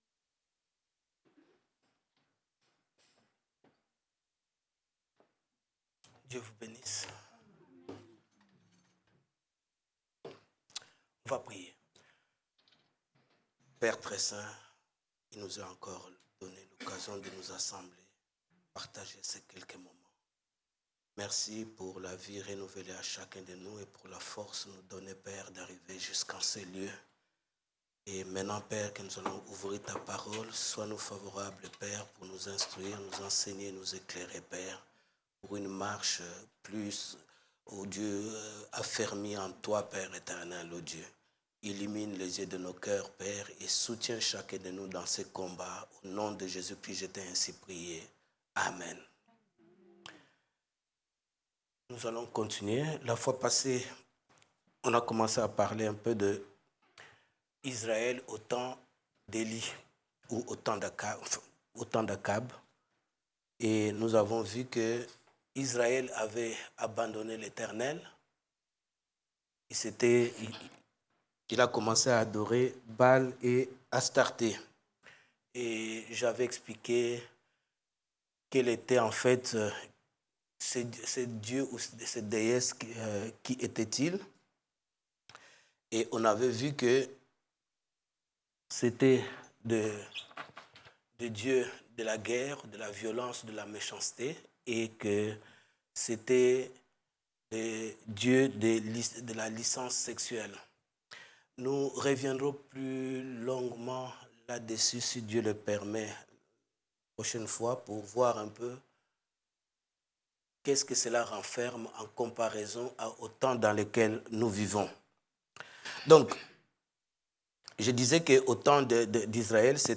Catégorie: Prédications